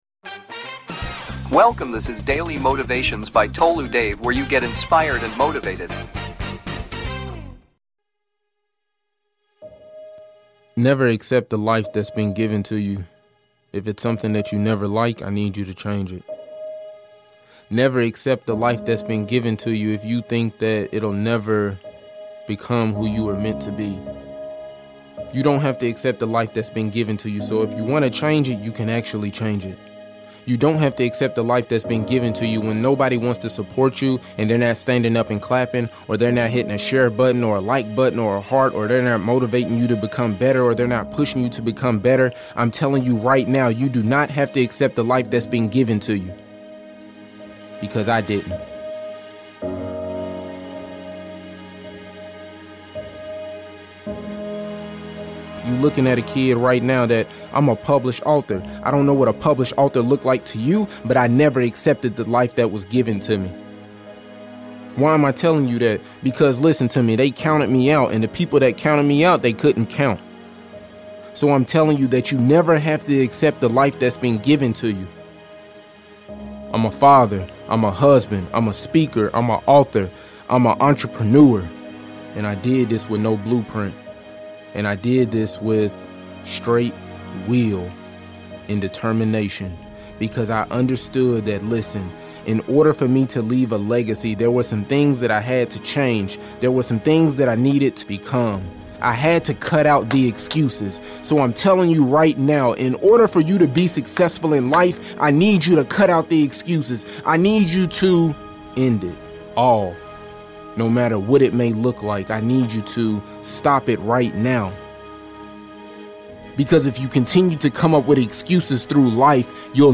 CUT THE EXCUSES - Powerful Motivational Speech for Success in Life